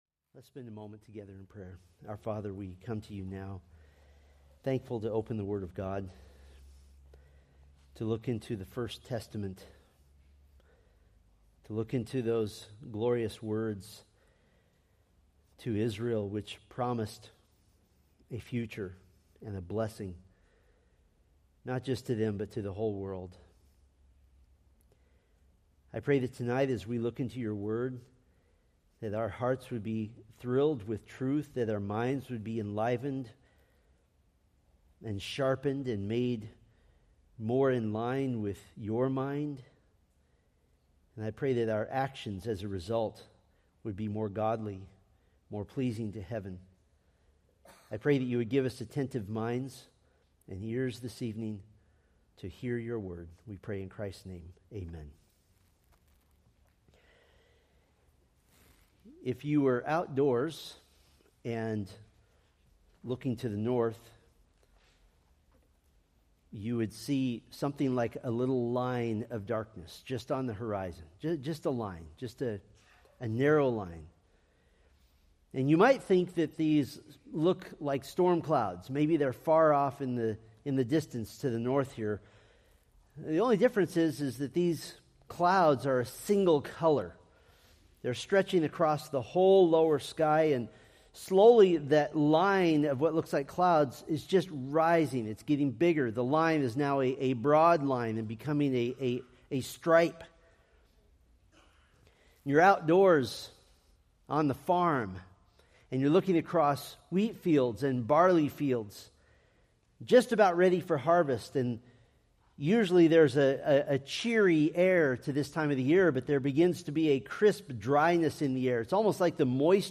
From the Millennium: Old Testament Witnesses sermon series.
Sermon Details